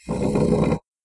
描述：主席脚下每晚成为英语角R1+at822录音录音时间：2007/7/3
标签： 大学 英语角 夜晚 毛主席像
声道立体声